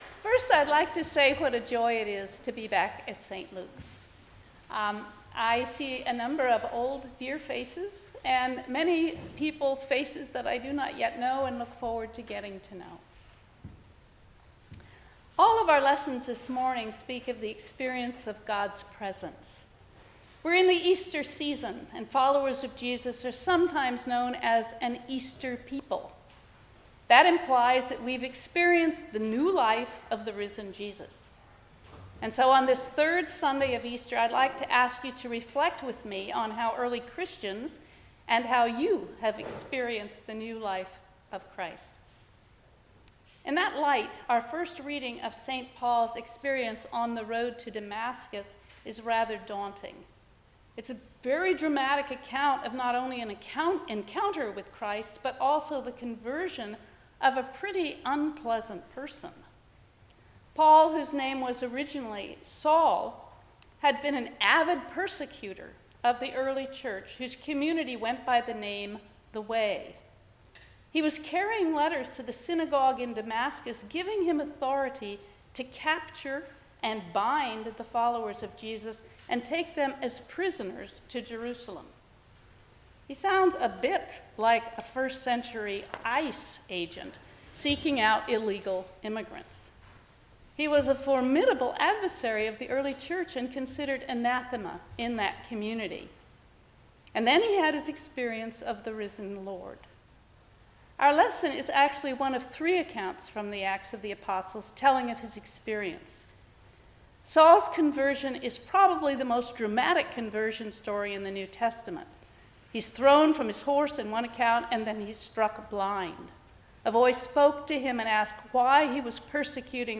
Service Type: 10:00 am Service